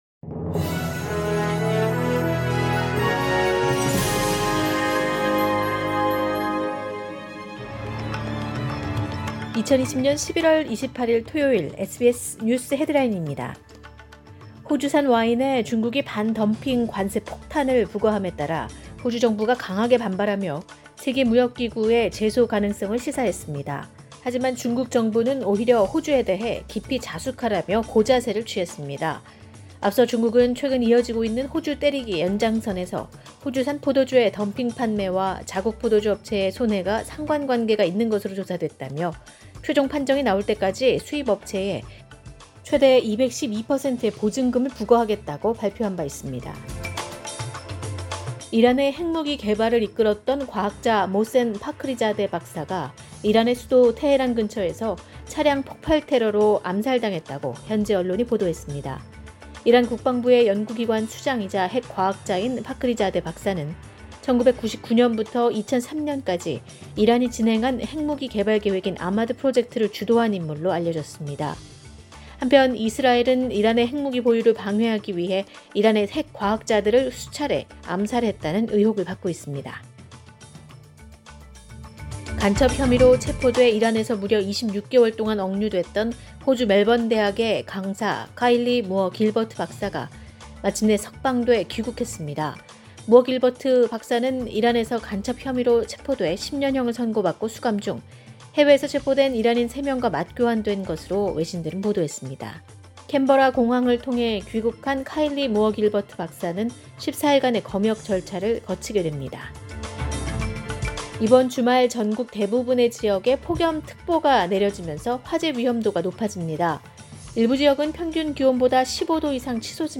SBS News Headlines…2020년 11월 28일 오전 주요 뉴스